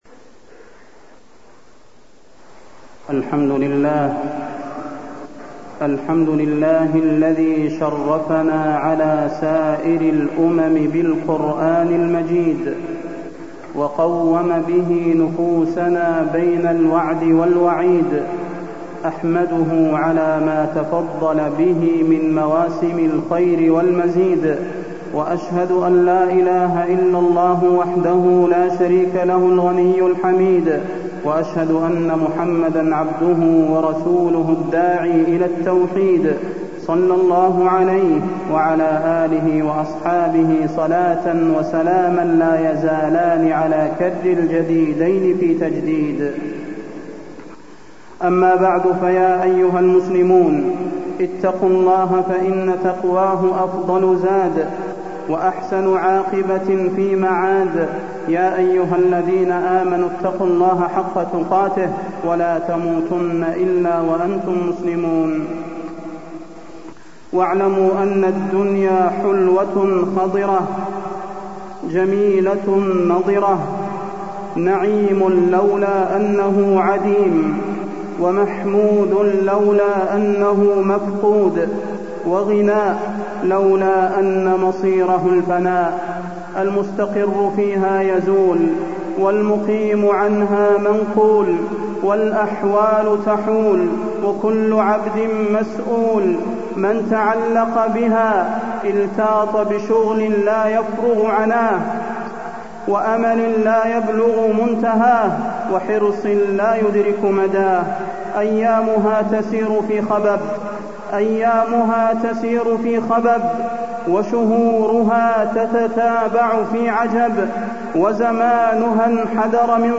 تاريخ النشر ٣ رمضان ١٤٢٣ هـ المكان: المسجد النبوي الشيخ: فضيلة الشيخ د. صلاح بن محمد البدير فضيلة الشيخ د. صلاح بن محمد البدير شهر رمضان